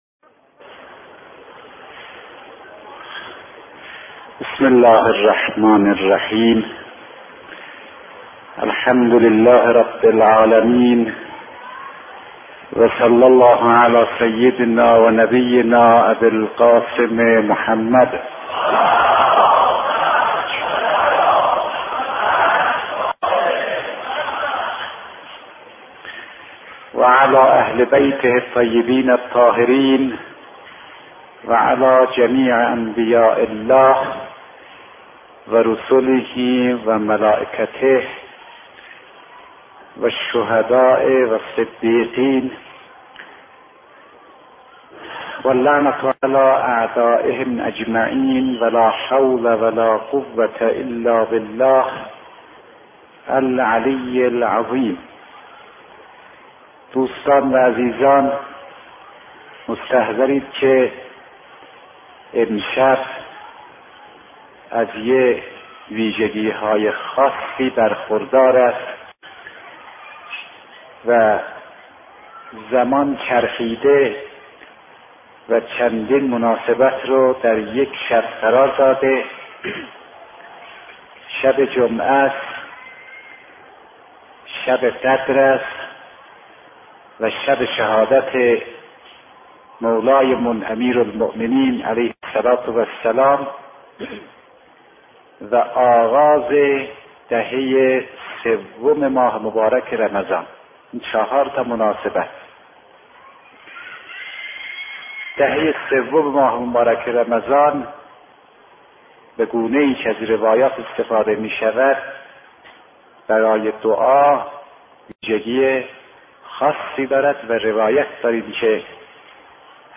این خطابه با حمد و ستایش پروردگار آغاز می‌شود و به بیان فضائل شب قدر و همزمانی آن با شب شهادت امیرالمؤمنین علی (علیه‌السلام) می‌پردازد.